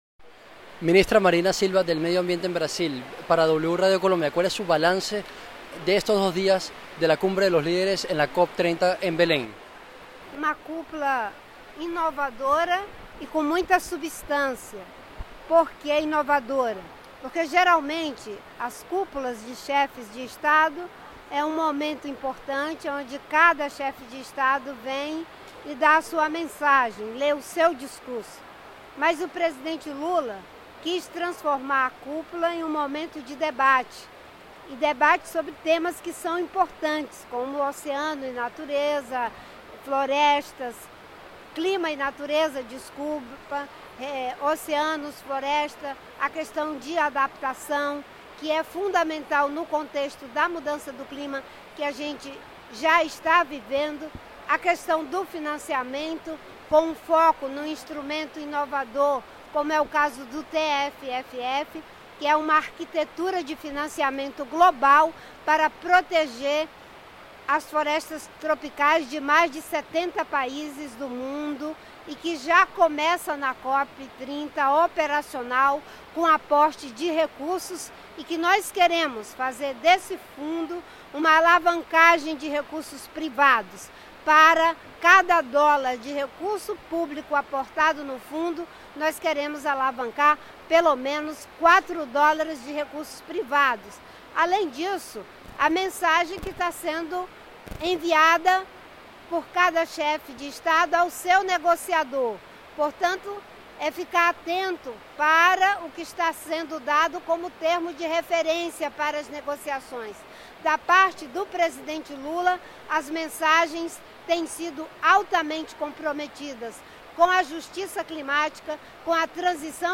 Marina Silva, ministra de Ambiente de Brasil, habló en W Radio previo al inicio de la COP30.
Este viernes, 7 de noviembre, habló en los micrófonos de W Radio la ministra de Ambiente de Brasil, Marina Silva, quien expresó su perspectiva de la Cumbre de los Líderes previo al inicio de la COP30.